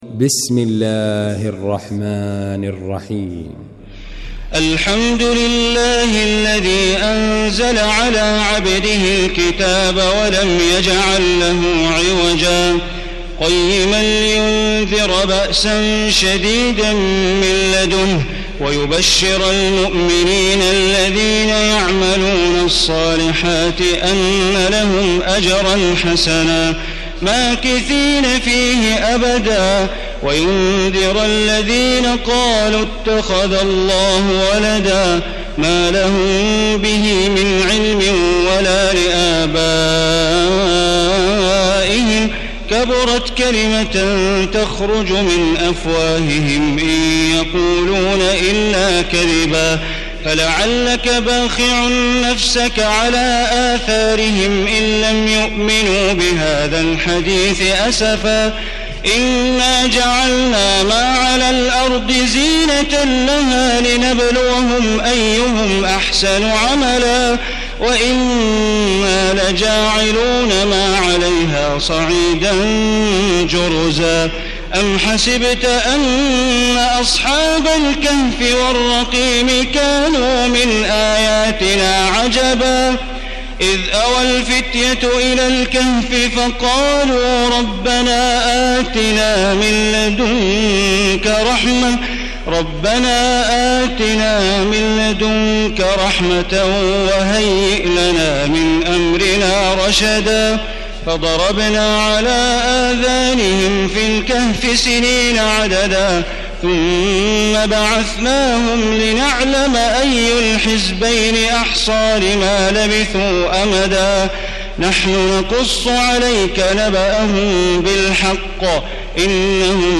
المكان: المسجد الحرام الشيخ: معالي الشيخ أ.د. بندر بليلة معالي الشيخ أ.د. بندر بليلة سعود الشريم الكهف